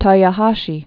(tôyô-häshē)